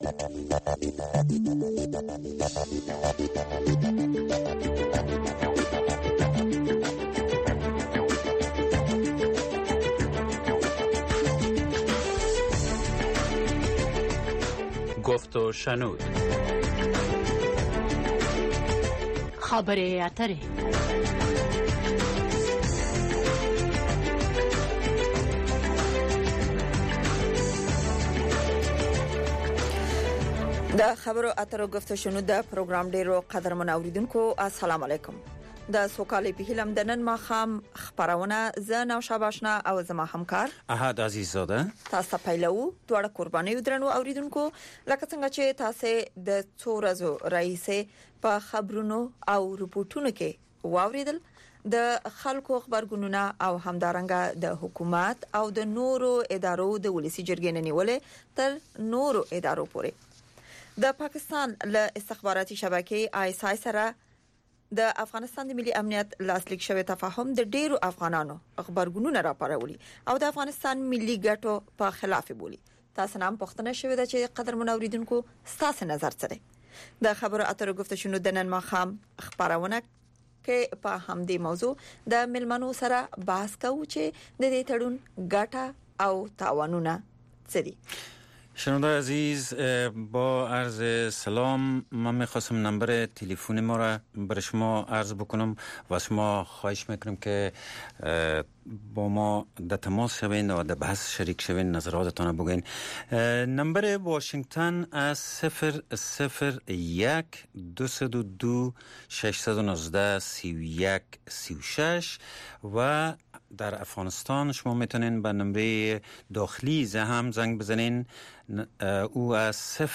په دغې خپرونه کې د روانو چارو پر مهمو مسایلو باندې له اوریدونکو او میلمنو سره خبرې کیږي.